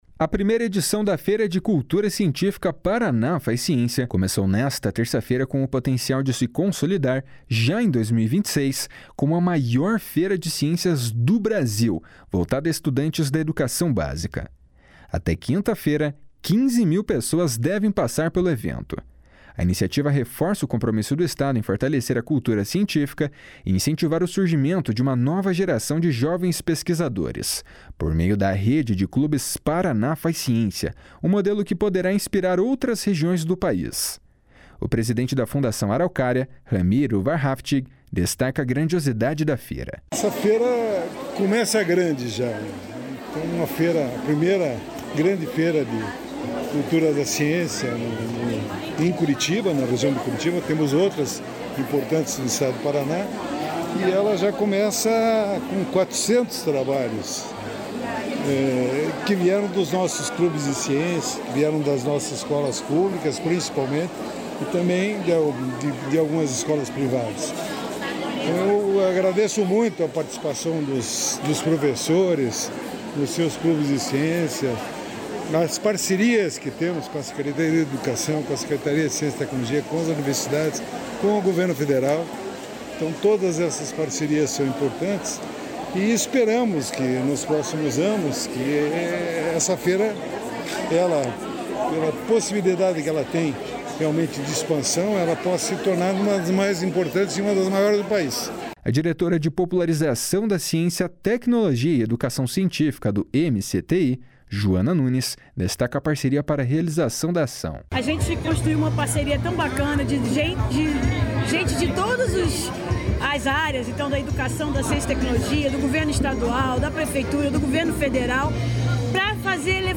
O presidente da Fundação Araucária, Ramiro Wahrhaftig, destaca a grandiosidade da feira.
A diretora de Popularização da Ciência, Tecnologia e Educação Científica do MCTI, Juana Nunes, destaca a parceria para a realização da ação.